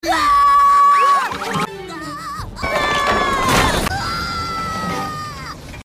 meatball screaming.